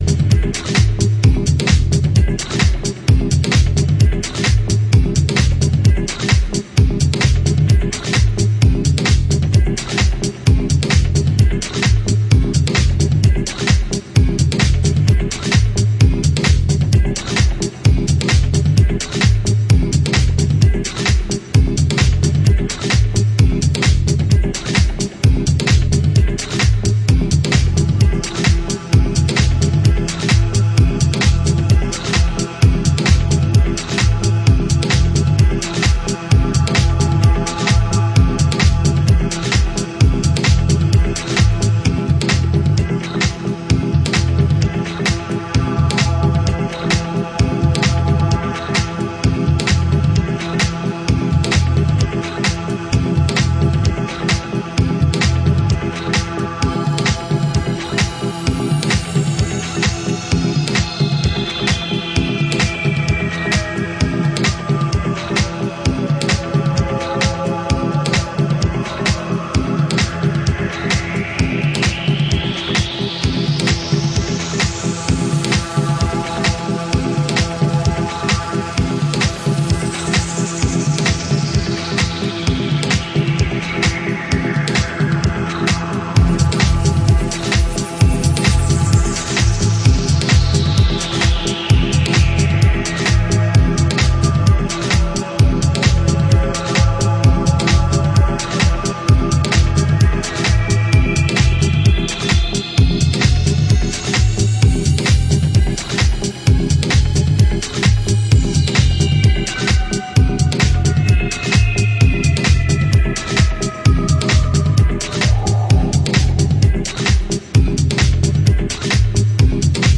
本シリーズの特色はパーカッシブでカオスなファンクネスを持ったDeep Hous...